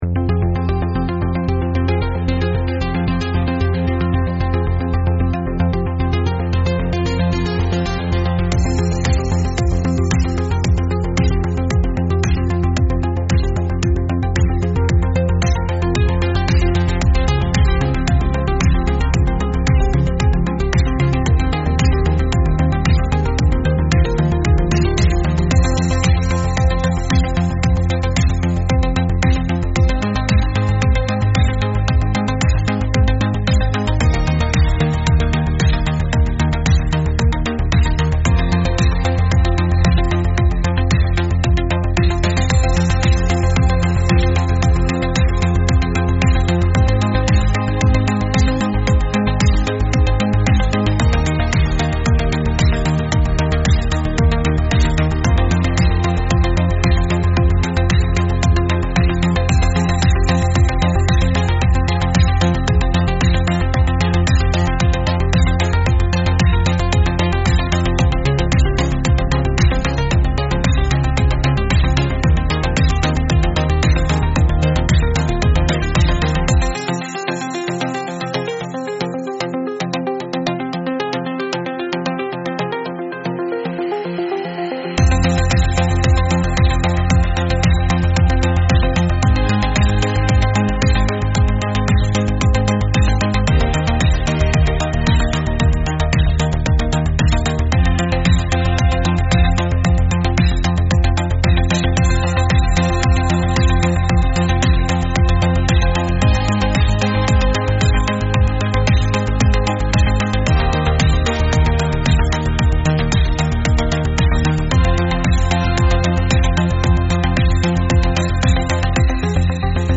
Instrumental - Real Liberty Media DOT xyz